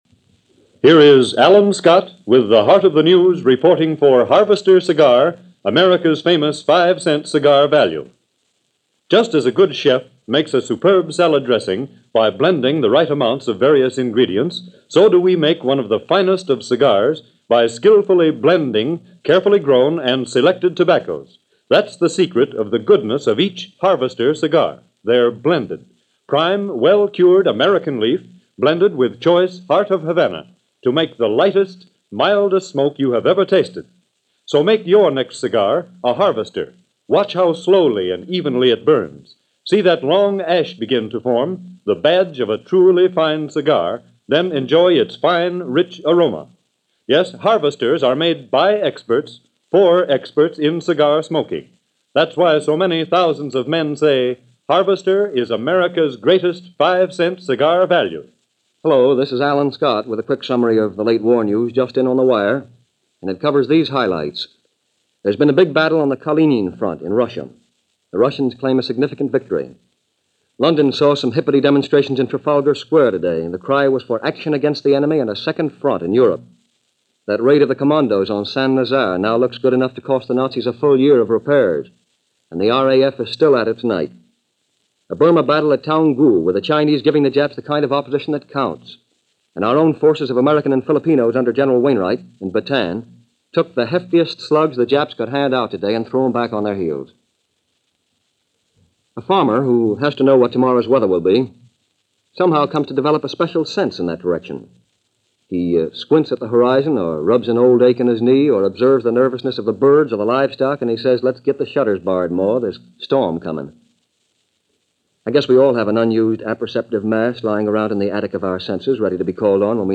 – WGN – News for March 29, 1942 –